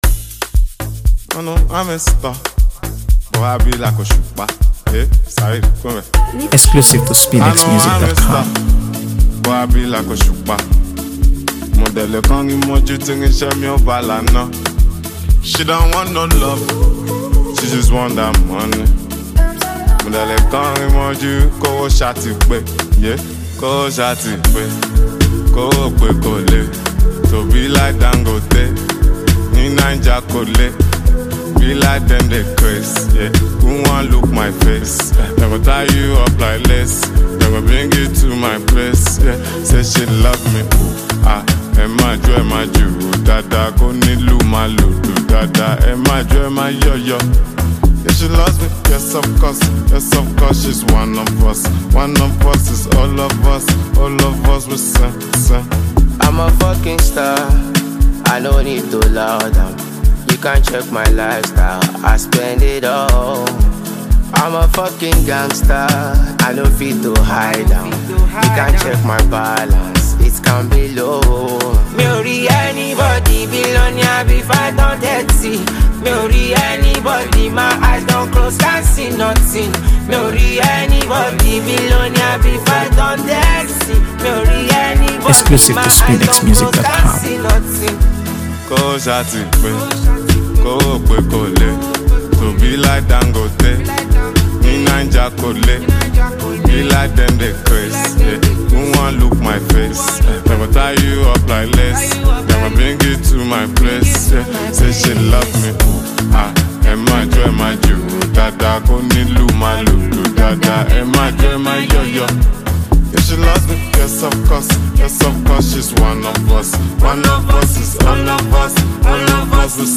AfroBeats | AfroBeats songs
The song has an irresistible groove
who mixes smooth Afrobeat beats with lively percussion.